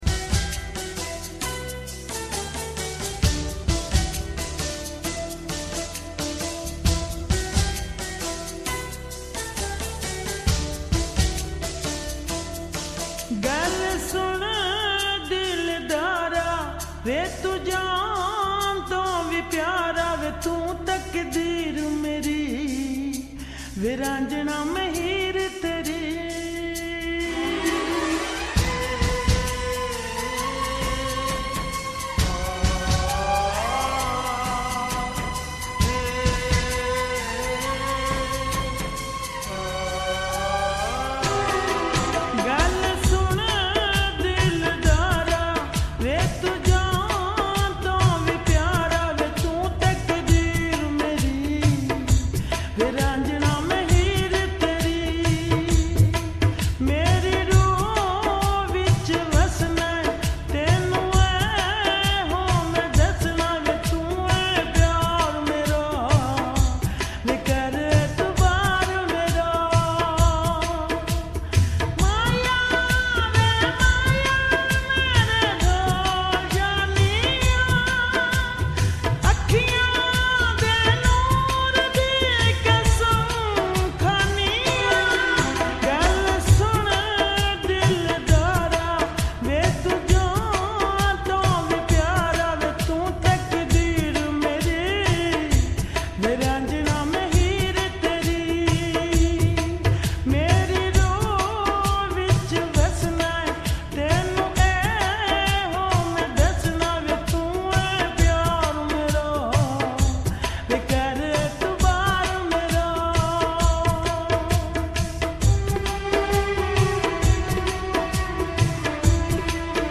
slow and reverb full song